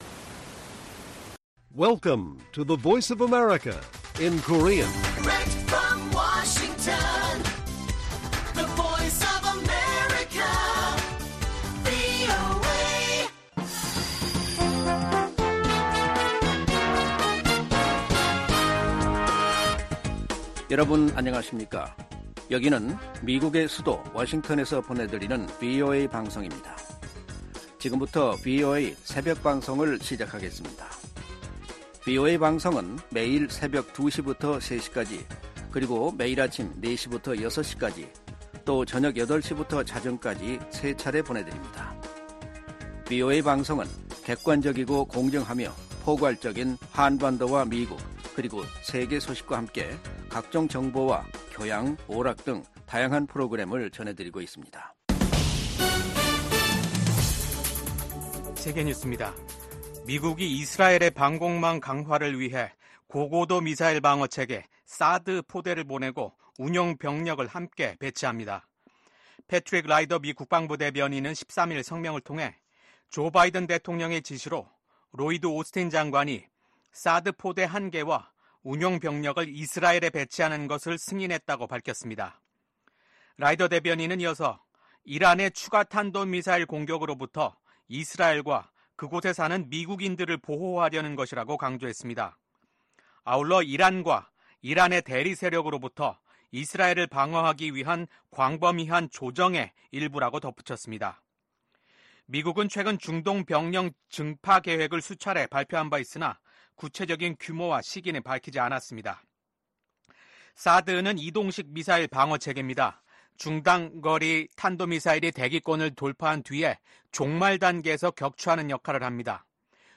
VOA 한국어 '출발 뉴스 쇼', 2024년 10월 15일 방송입니다. 북한이 한국 측 무인기의 평양 침투를 주장하면서 한국과의 접경 부근 포병 부대들에게 사격 준비 태세를 지시했습니다. 미국 북한인권특사는 북한에서 공개재판과 공개처형이 늘어나는 등 북한 인권 실태가 더욱 열악해지고 있다고 지적했습니다.